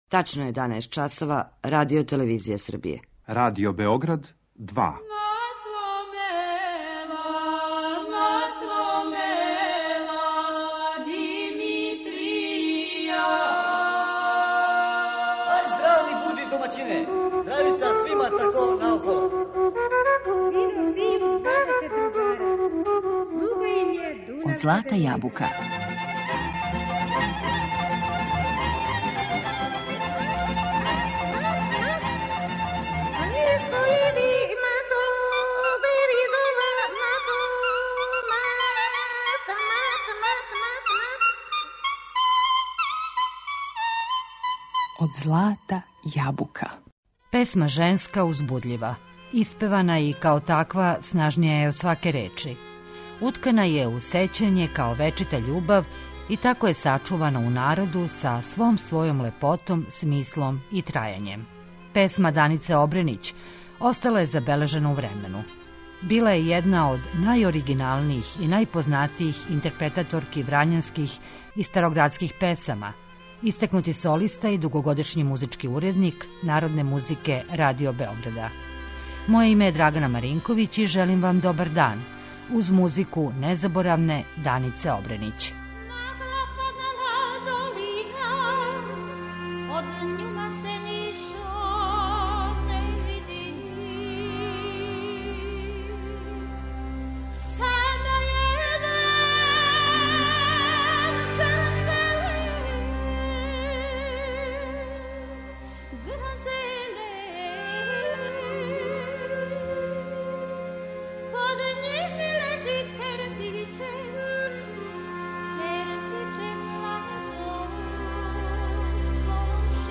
Песма Данице Обренић, којој смо посветили данашње издање емисије, остала је забележена у времену.